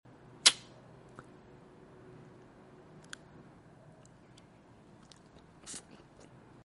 ASMR | Anya & Becky sound effects free download
Anya & Becky Spy x Family Gum 🍬 Cute Packaging & Relaxing Sounds